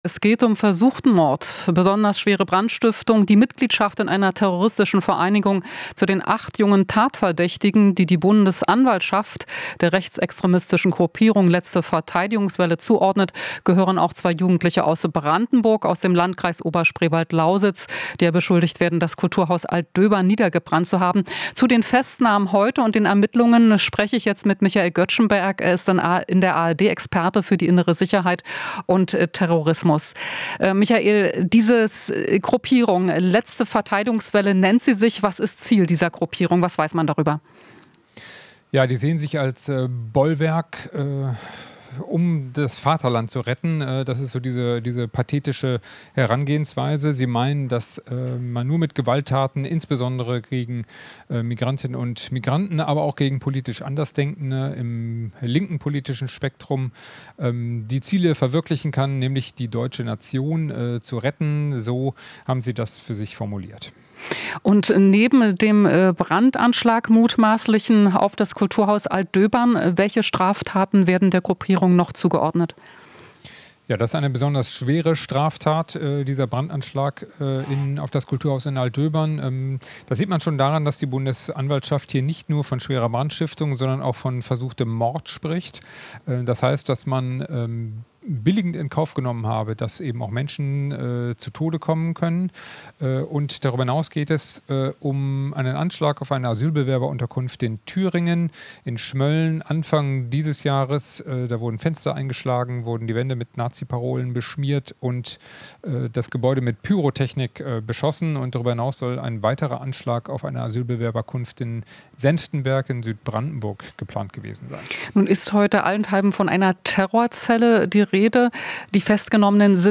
Interview - Was über die Terrorzelle "Letzte Verteidigungswelle" bekannt ist